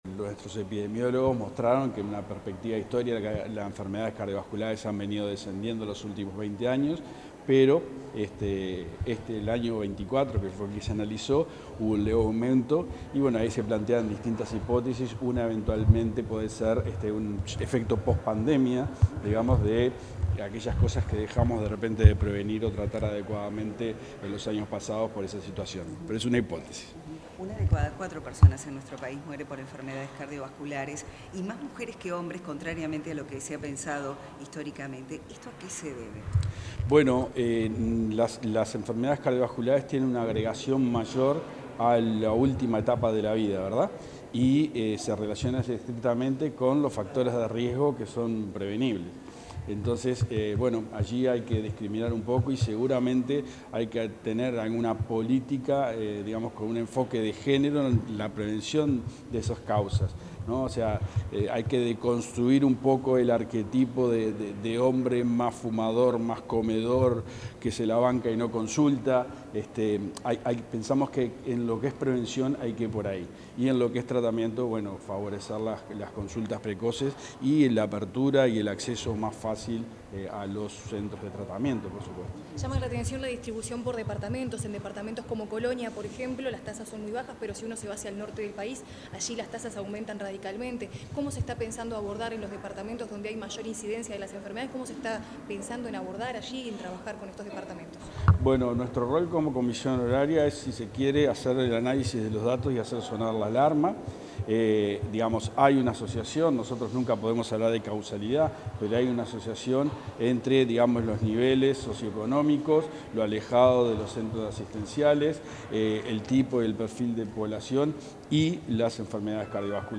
Declaraciones del presidente de la Comisión Honoraria para la Salud Cardiovascular, Alejandro Cuesta